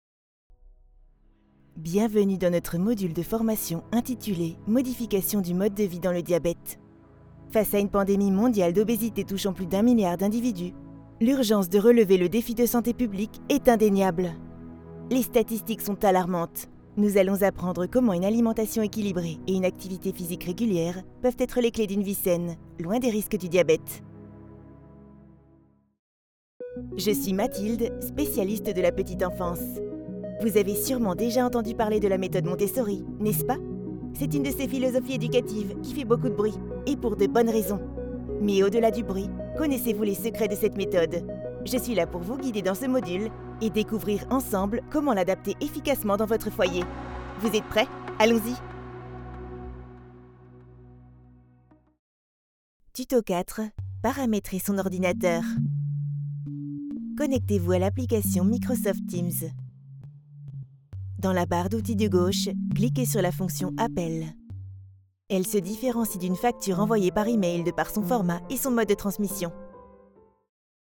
Kommerziell, Junge, Verspielt, Warm, Sanft
E-learning
She has a medium voice.